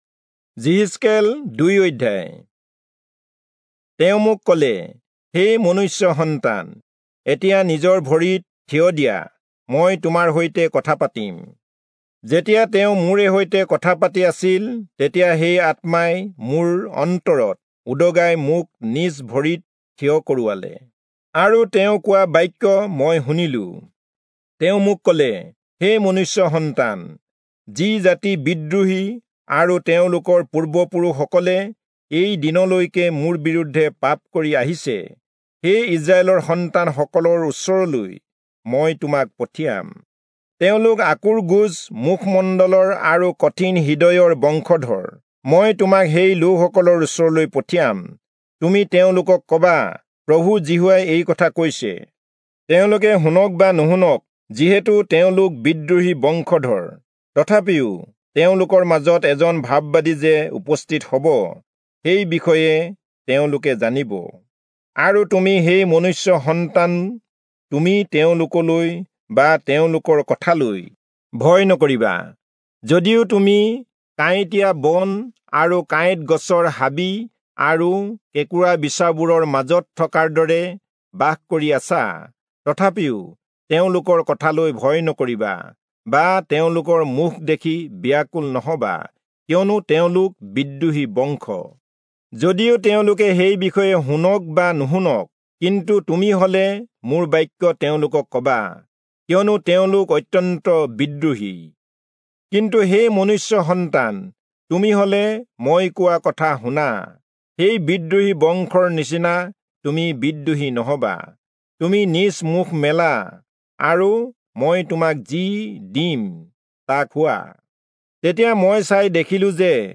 Assamese Audio Bible - Ezekiel 8 in Tov bible version